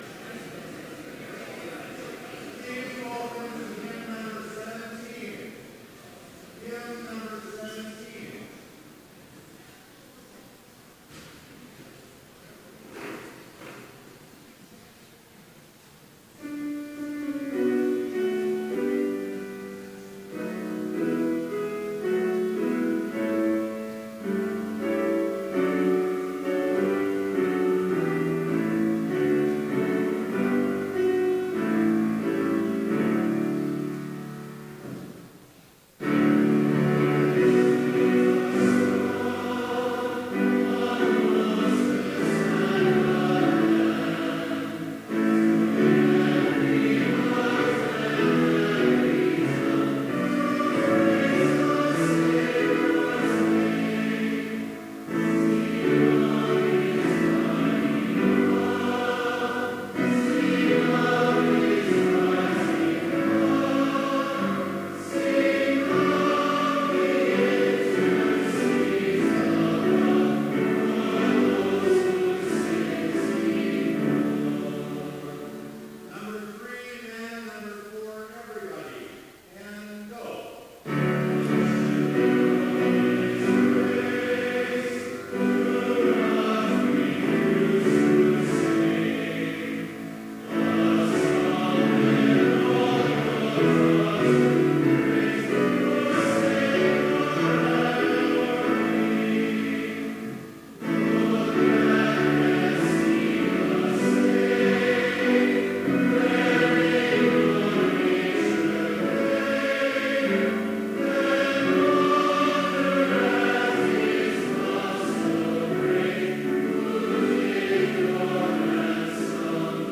Chapel service on May 1, 2018, at Bethany Chapel in Mankato, MN,
Complete service audio for Chapel - May 1, 2018